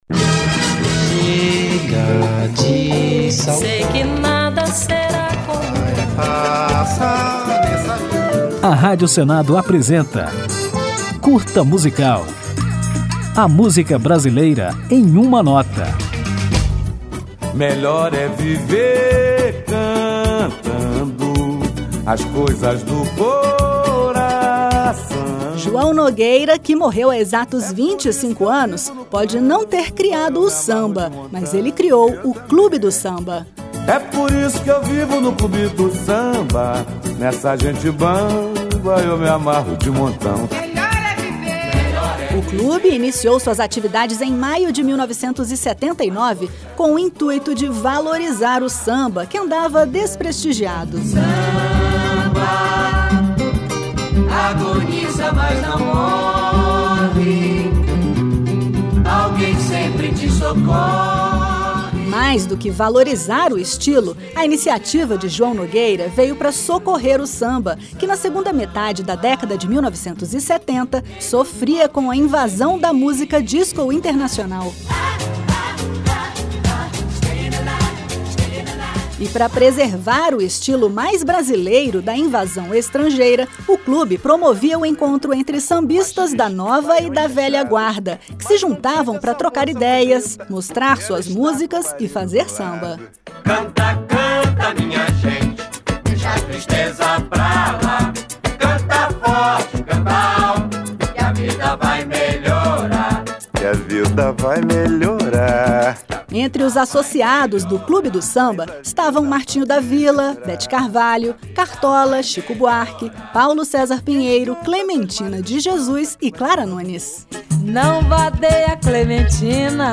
Conjunto de pílulas radiofônicas sobre a MPB, nas quais o ouvinte pode conferir fatos, curiosidades, informações históricas e ainda ouvir uma música ao final de cada edição.